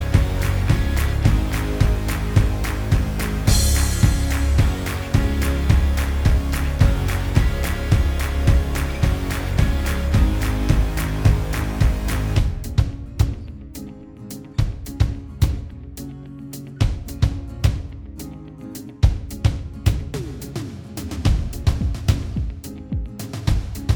Minus All Guitars Pop (2010s) 3:08 Buy £1.50